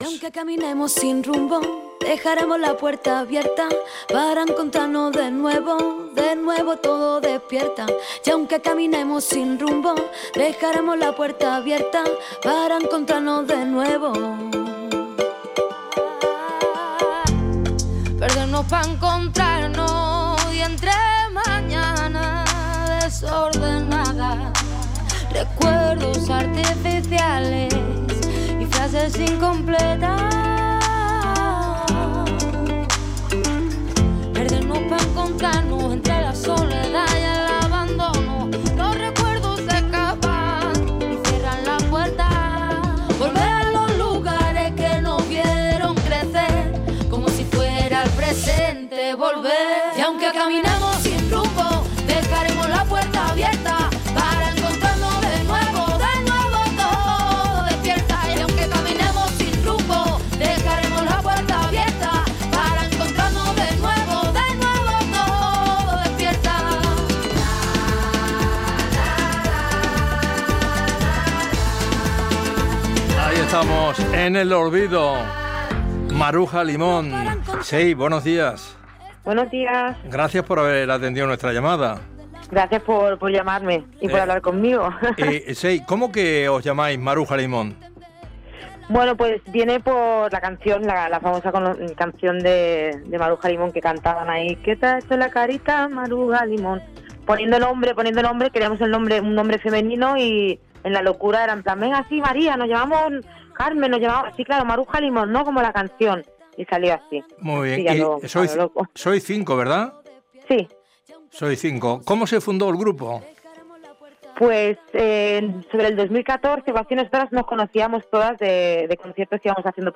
La entrevista a Maruja Limón
Justo Molinero ha hablado con una de las componentes de Maruja Limón. Han hablado de la trayectoria del grupo y del próximo concierto que el jueves 18 de junio a las 20h podrás ver a través de nuestro canal de youtube dentro del ciclo Flamencat, organizado por la Generalitat de Catalunya.
entrevista-tlf-maruja-limon.mp3